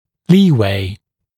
[‘liːweɪ][‘ли:уэй]припуск, запас
leeway.mp3